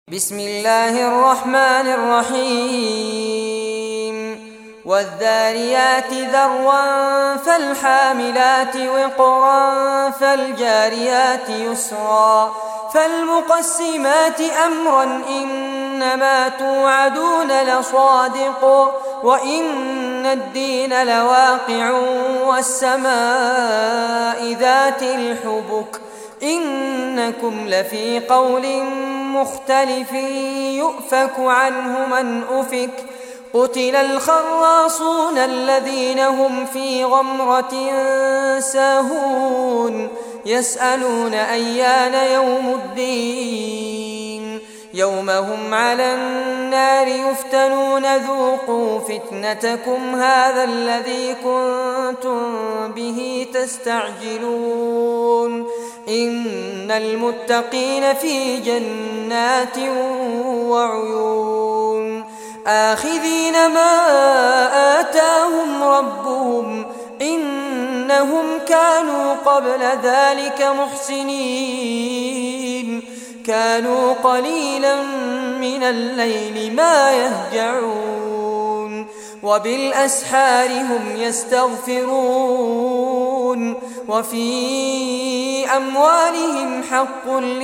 Surah Adh-Dhariyat Recitation by Fares Abbad
Surah Adh-Dhariyat, listen or play online mp3 tilawat / recitation in Arabic in the beautiful voice of Sheikh Fares Abbad.